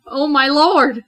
另一个惊讶的OOOH
描述：大声笑我现在在玩电子游戏时记录我的声音，这样我就可以保存我说的某些东西，你知道，用于卡通和东西的真实反应。很多我的声音片段来自于玩跳跃比赛的游戏，而这就是我的尖叫声和OOOOOOOH来自哪里。
标签： 讲话 谈话 恐慌 语音 英语 女性 惊起 害怕 女人 感叹
声道立体声